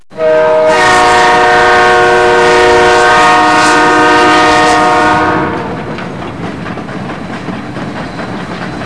train4.wav